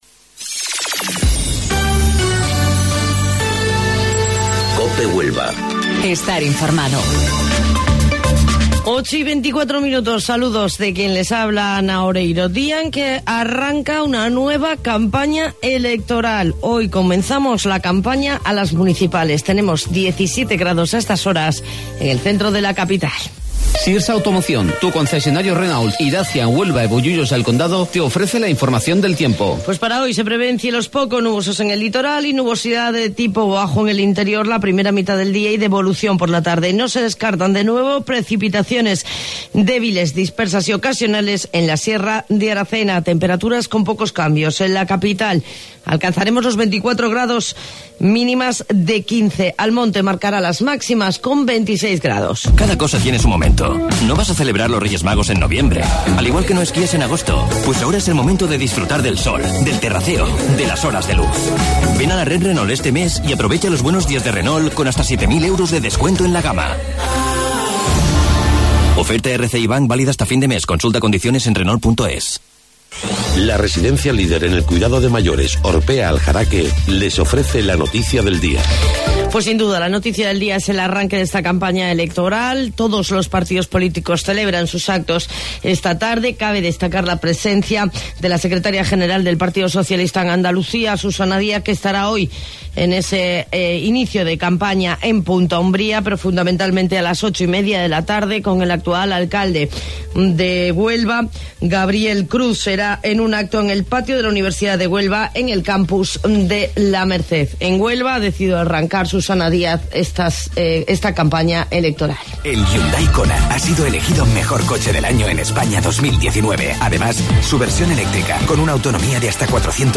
AUDIO: Informativo Local 08:25 del 9 de Mayo